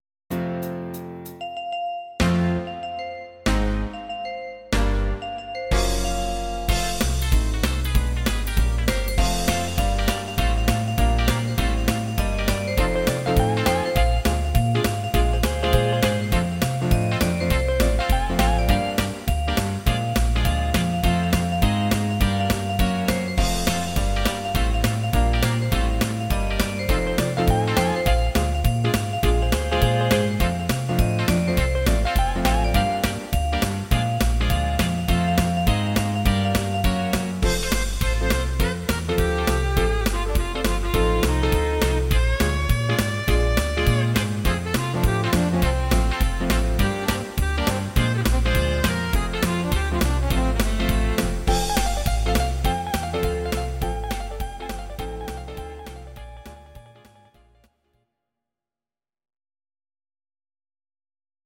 Please note: no vocals and no karaoke included.
Your-Mix: Rock (2958)